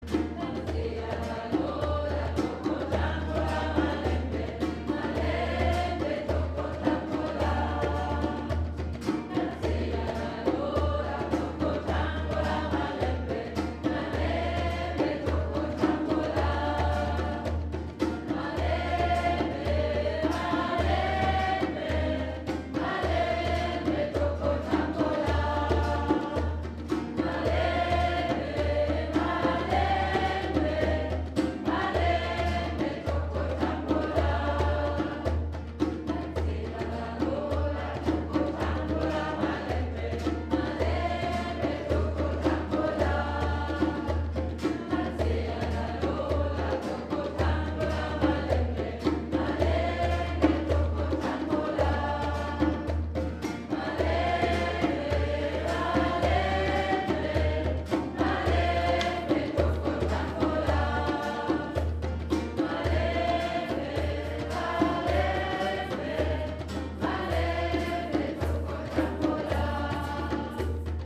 CHANTABEND  "AFRIKA"   MAI 2014
aber auch besinnliche,  afrikanische Chants gesungen, getrommelt
An der Djembe begleitete uns: